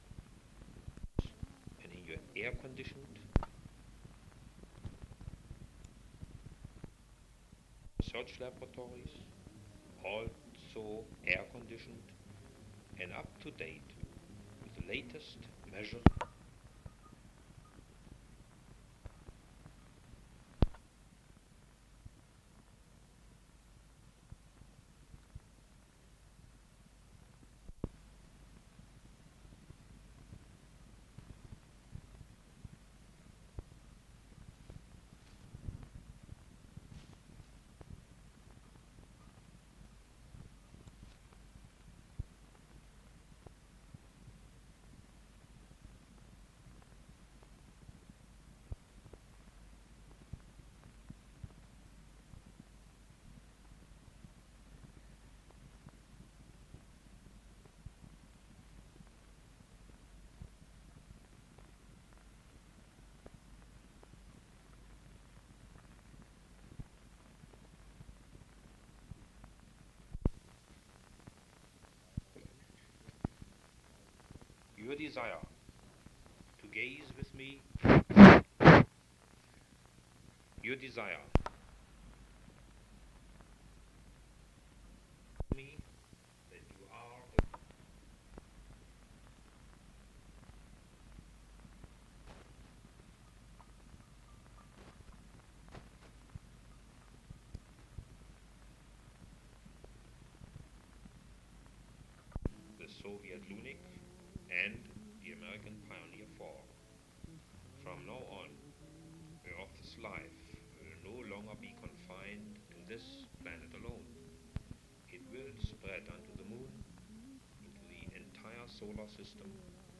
Speeches.
Description Speech by Dr. Wernher von Braun on side 1. Pertains to early NASA projects and future plans, circa 1960. Speech taped over something else, audio very faint at points.